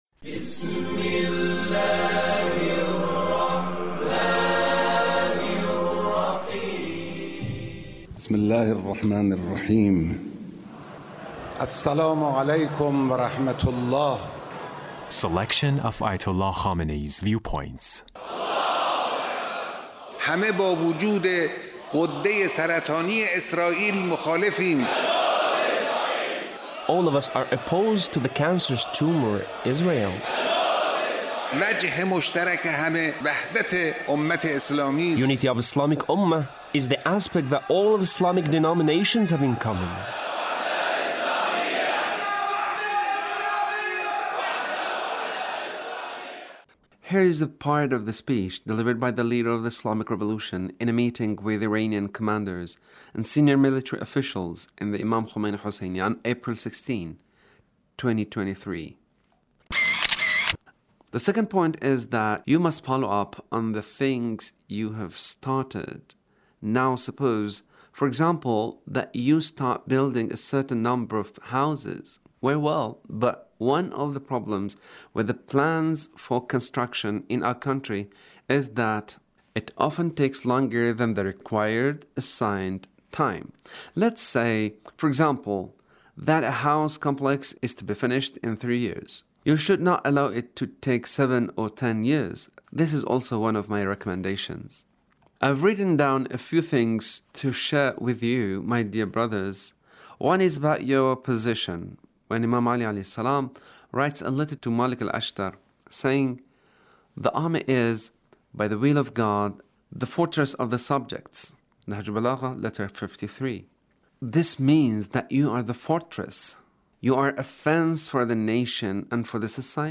Leader's Speech in a meeting with Iranian commanders and senior military officials